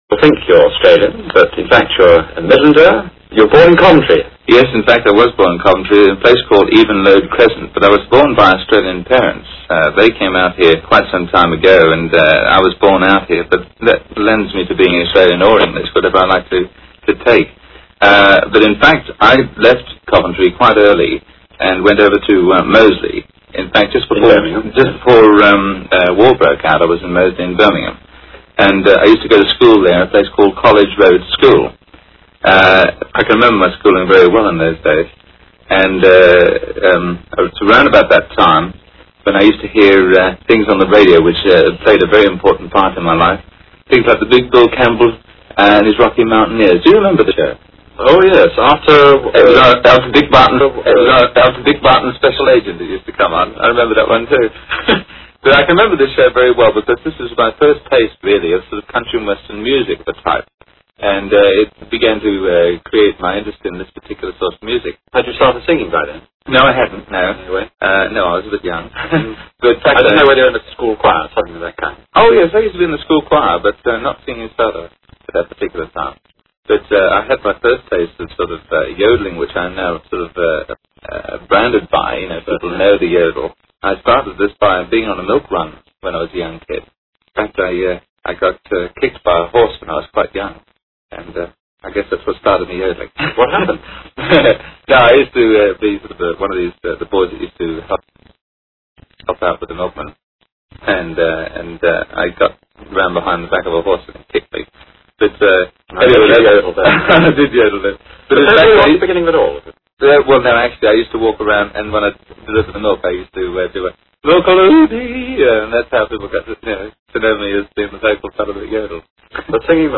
fiInterview.wav